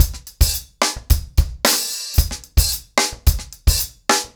TimeToRun-110BPM.29.wav